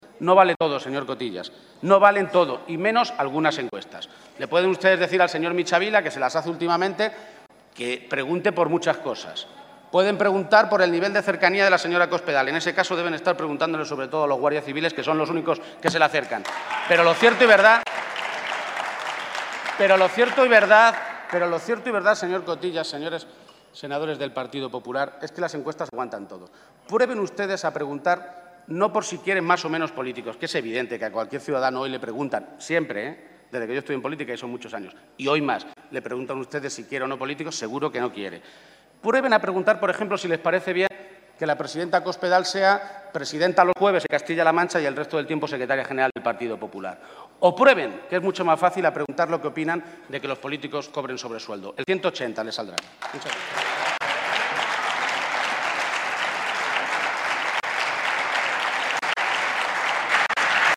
Emiliano García-Page durante su intervención en el Senado
Audio Page-segunda intervención Senado 2